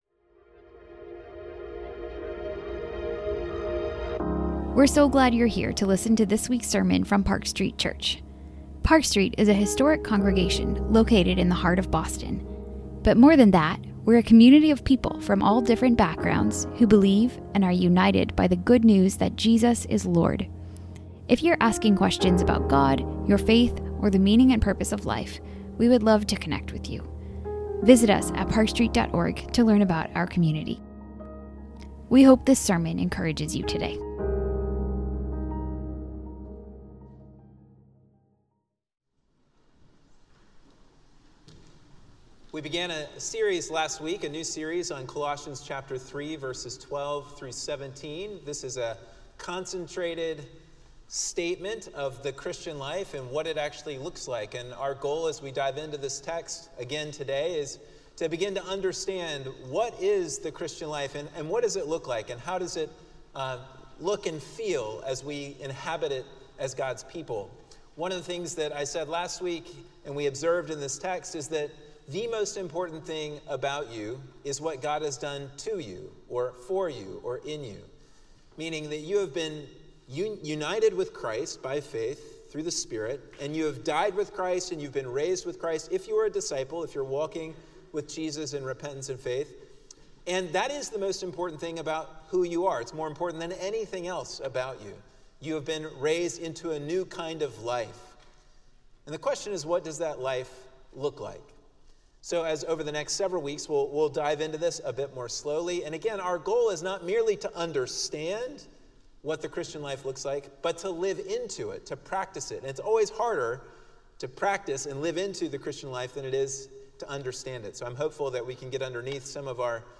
Sermons - Park Street Church